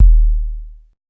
DWS KICK3 -L.wav